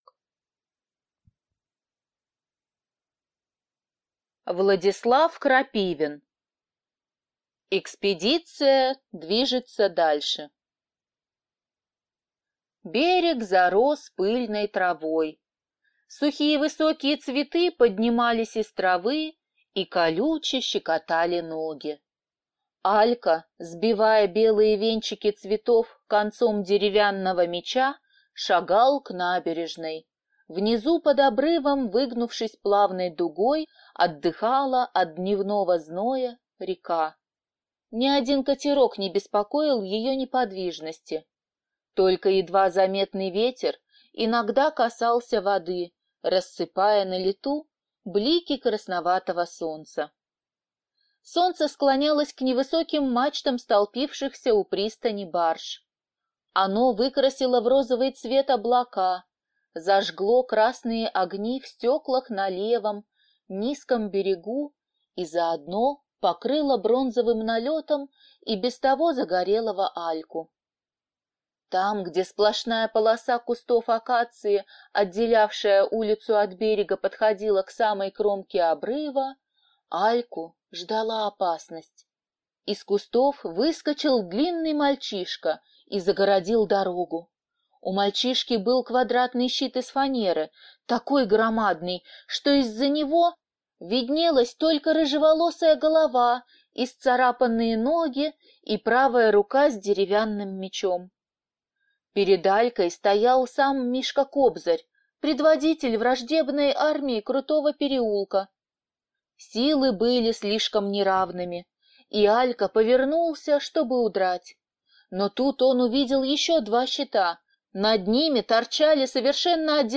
Аудиокнига Экспедиция движется дальше | Библиотека аудиокниг